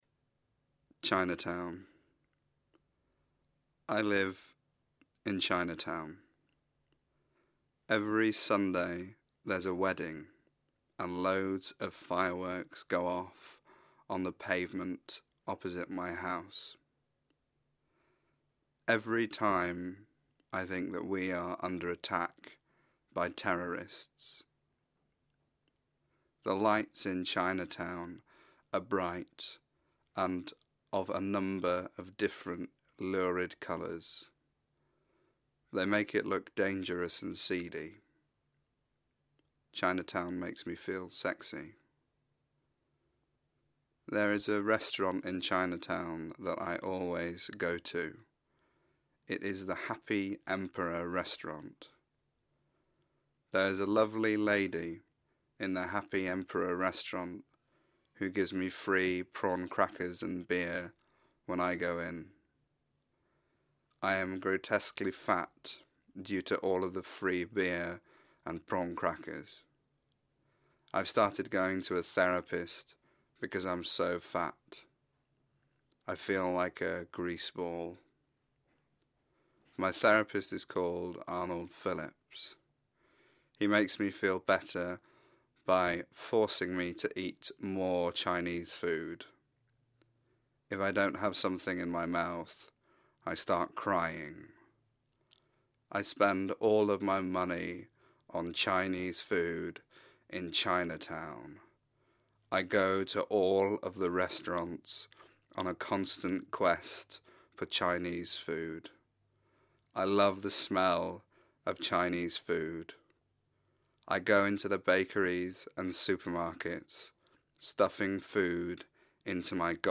Please click on each of the five links below to download or stream the individual stories, as told by their authors: